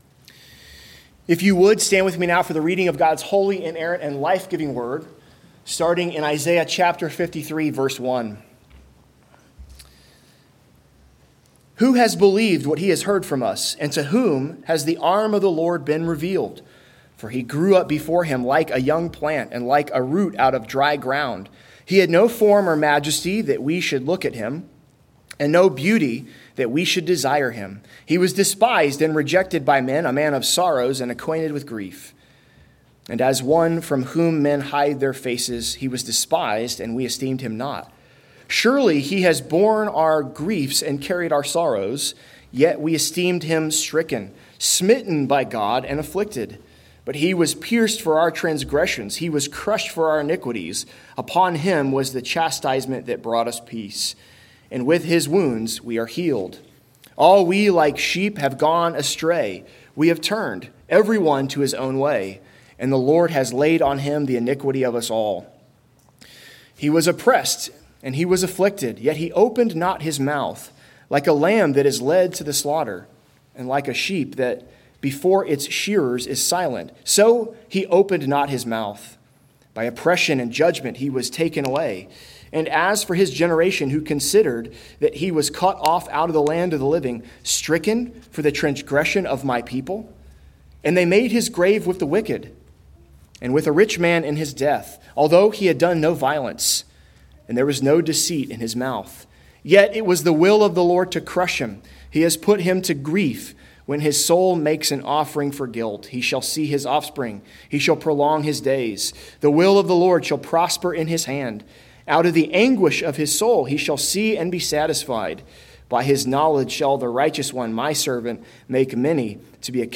Galatians 2.20-21 Service Type: Sunday Worship The Gospel is Christ centered.